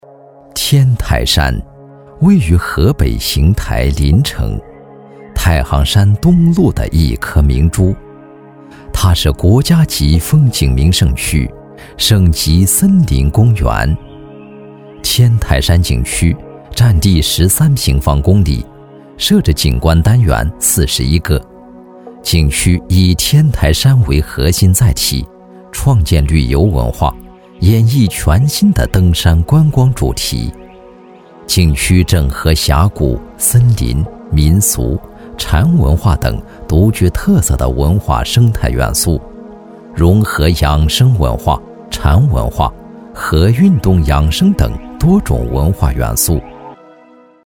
旅游宣传片男250号（天台
娓娓道来 旅游风光
磁性历史感男音，擅长风格旅游宣传片、专题、纪录片，企业宣传片题材。